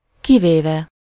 Ääntäminen
IPA : /ɛˈksɛpt/